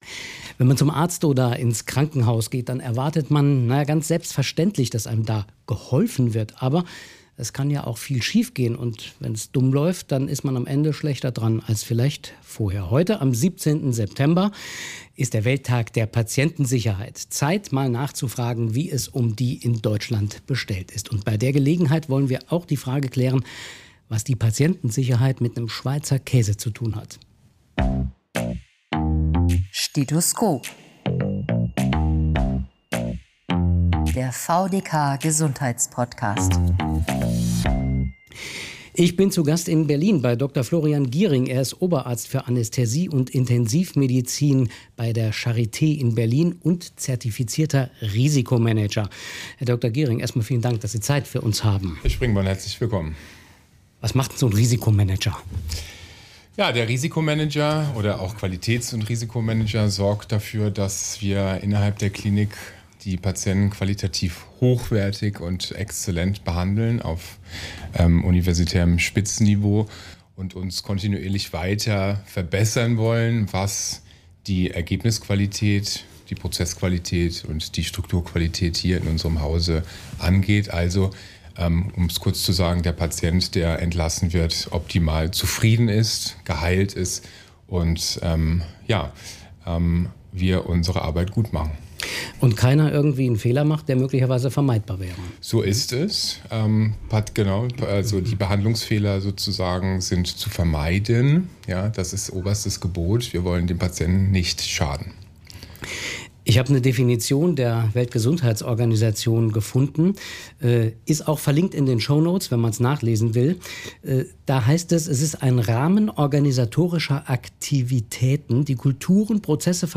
Anlass für uns, der Frage nachzugehen, wie es um die Patientensicherheit in Deutschland bestellt ist. Im Gespräch